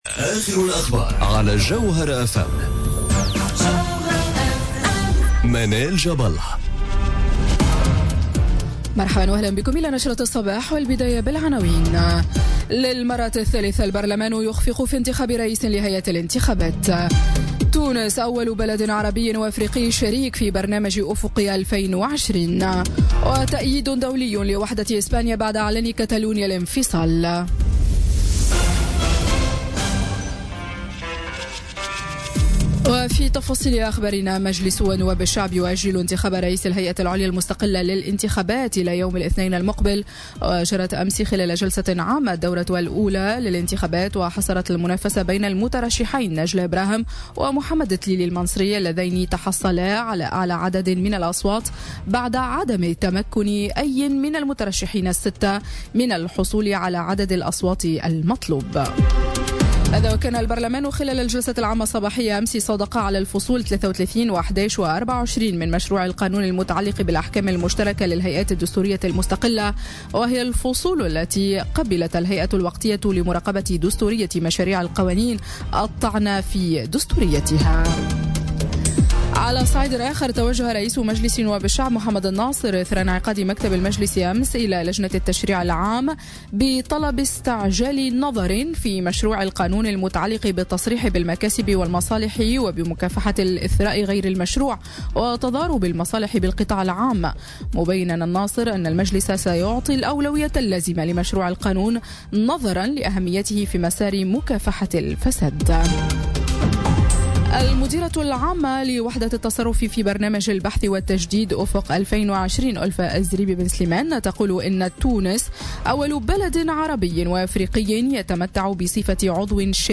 نشرة أخبار السابعة صباحا ليوم السبت 28 أكتوبر 2017